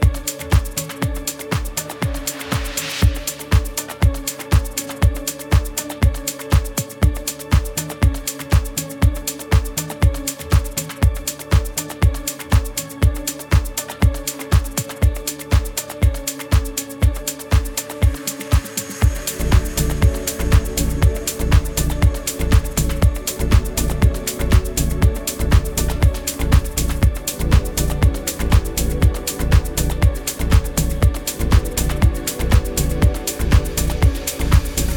Жанр: Электроника / Рок